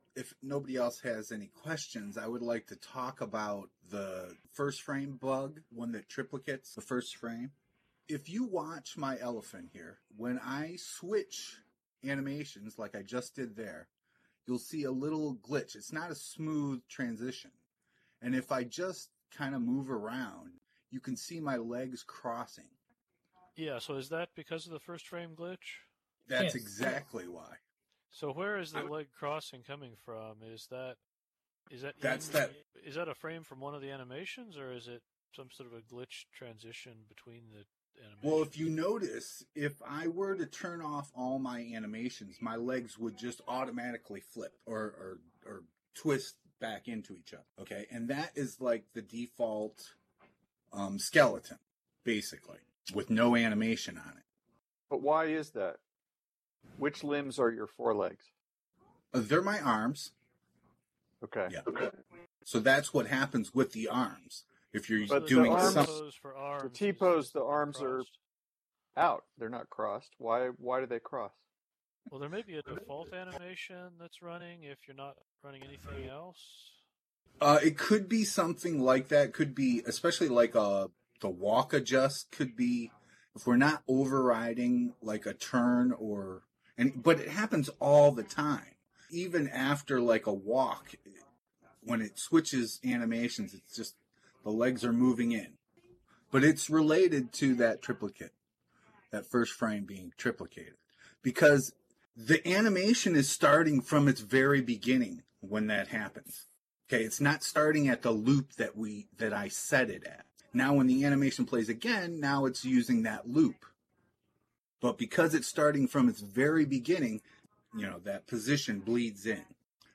General discussion on animation systems, including the server-side mechanism introduced a couple of years ago and problems in trying to identify actual causes for issues